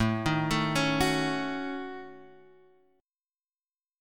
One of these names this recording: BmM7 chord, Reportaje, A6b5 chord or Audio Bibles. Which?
A6b5 chord